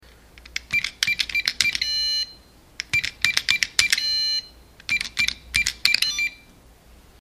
音效类别：机械